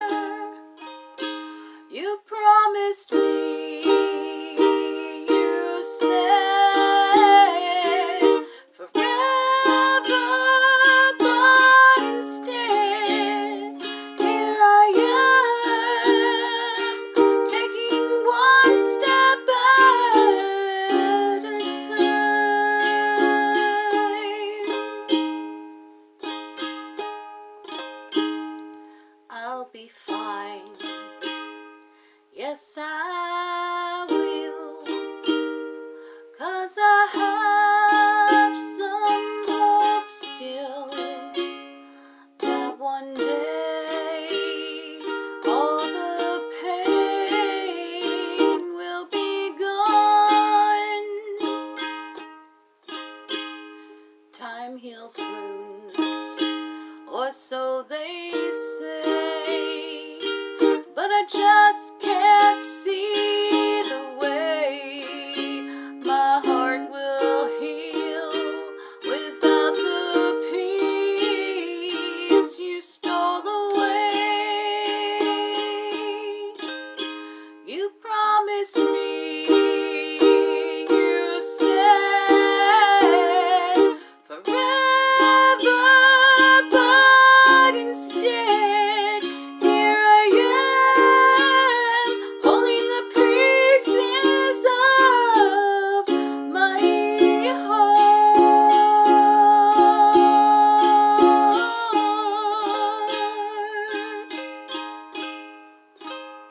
I'm not a performer/ singer, but just to include the melody...
Sad, melancholy song
Deffo a classic country ballad.